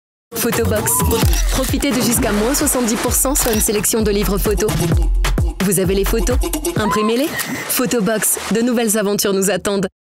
Female
Bright, Friendly, Versatile
NIKE - Natural inspirational.mp3
Microphone: TLM103